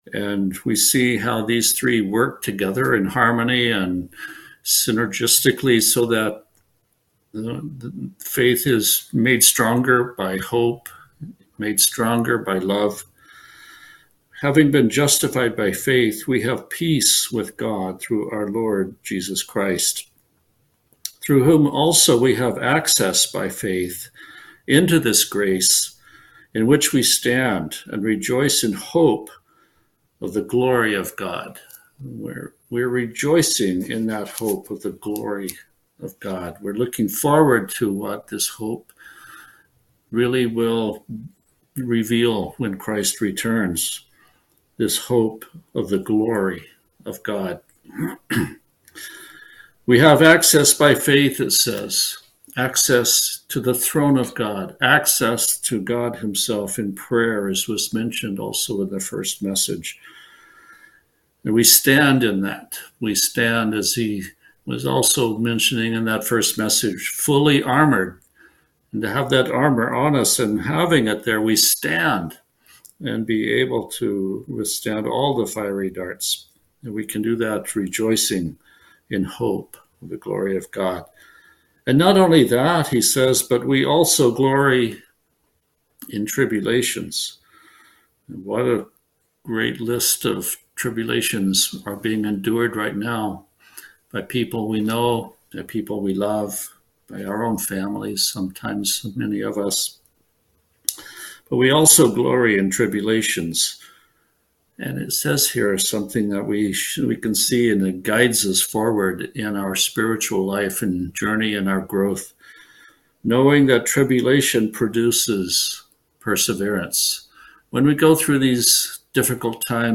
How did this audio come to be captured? Given in Olympia, WA Tacoma, WA